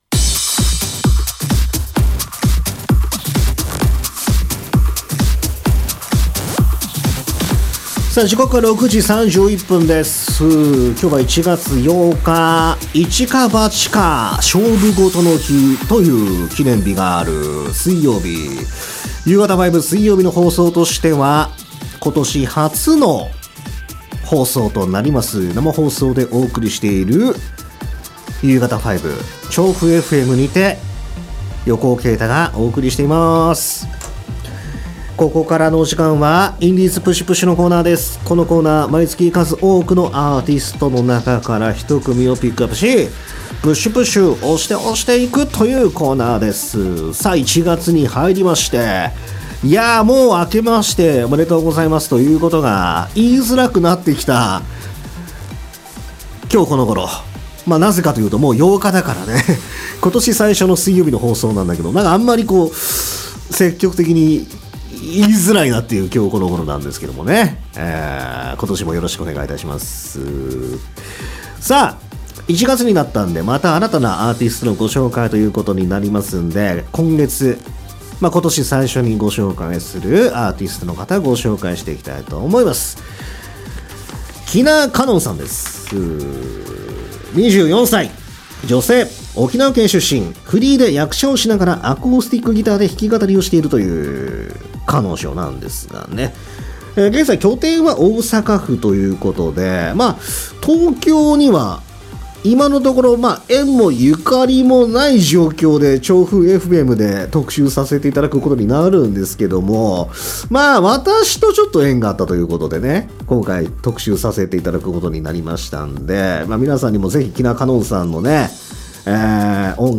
フリーで役者をしながらアコースティックギターで弾き語りをしている。